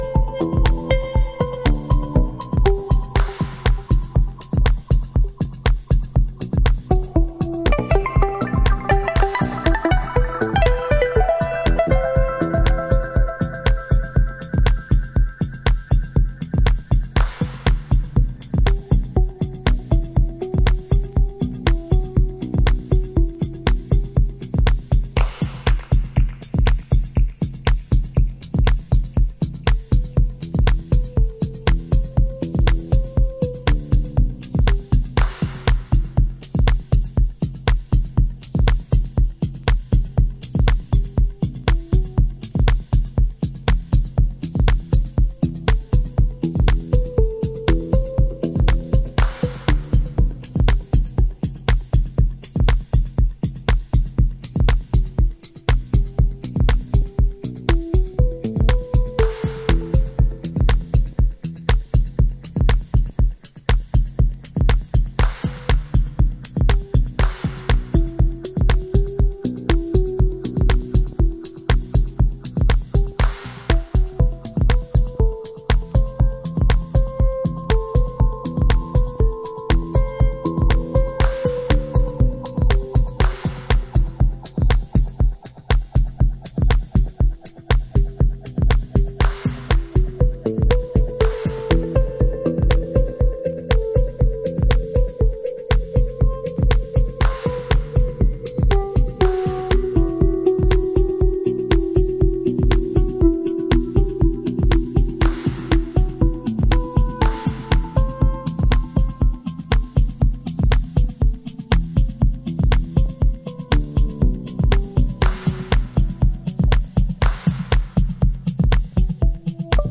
House / Techno